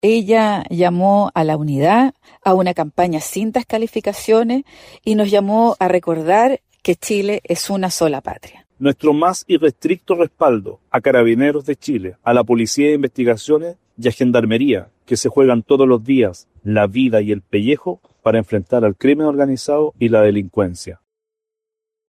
Frente a la arremetida de la derecha, la senadora Alejandra Sepúlveda, vocera del equipo de la abanderada del oficialismo, recordó que la candidata hizo énfasis en su llamado a hacer una campaña sin descalificaciones.
Por su parte, el diputado e integrante del comando, Eric Aedo (DC) afirmó que Jara “tiene un compromiso irrestricto con Carabineros.